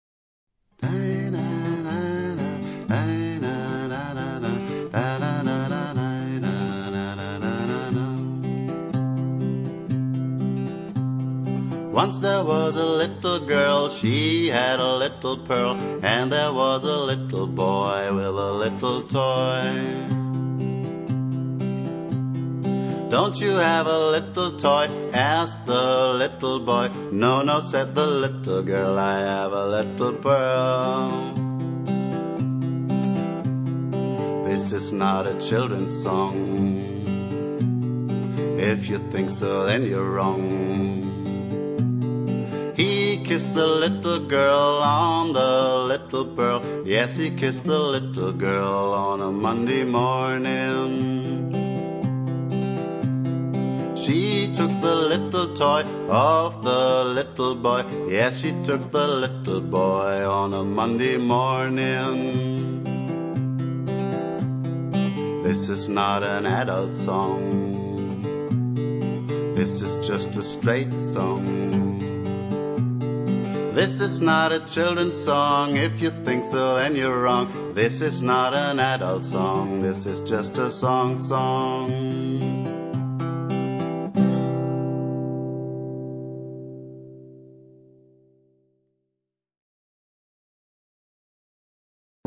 g + voc